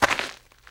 STEPS Gravel, Walk 04.wav